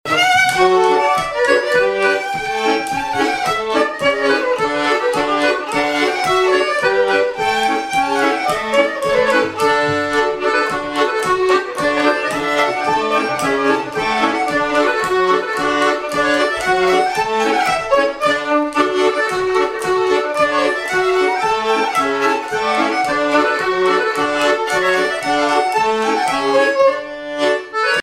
danse : quadrille : pastourelle
Répertoire de bal au violon et accordéon
Pièce musicale inédite